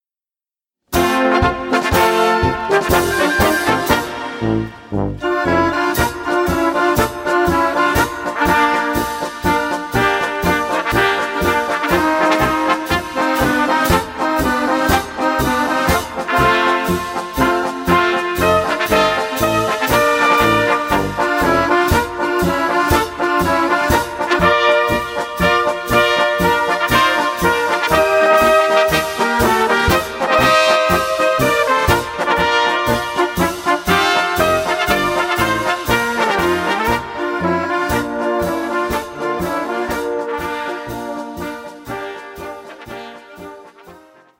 für Blasorchester